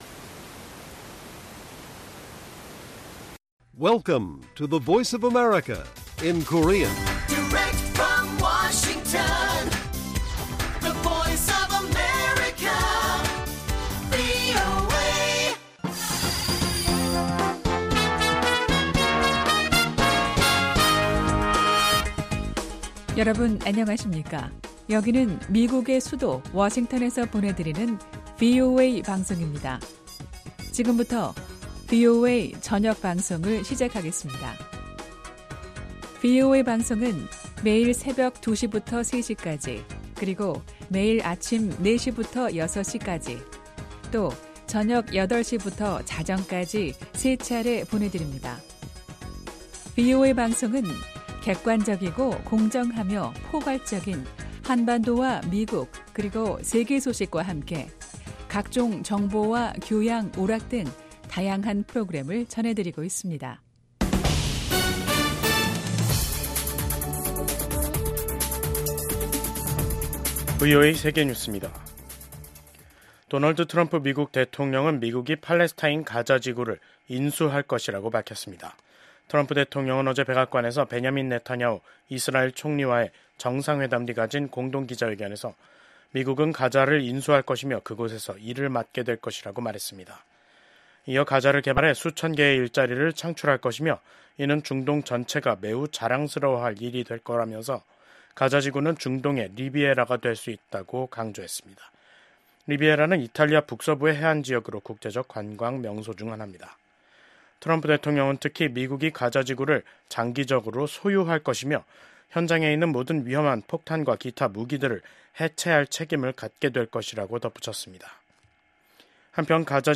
VOA 한국어 간판 뉴스 프로그램 '뉴스 투데이', 2025년 2월 5일 1부 방송입니다. 러시아에 파견돼 상당수 사상자가 발생한 북한 군이 쿠르스크 전선에서 최근 일시 퇴각했다는 소식이 전해지면서 추가 파병이 임박한 게 아니냐는 관측이 나오고 있습니다. 북대서양조약기구(나토. NATO)가 북한의 러시아 파병 증원설과 관련해 양국에 국제법 위반 행위를 즉각 중단할 것을 촉구했습니다.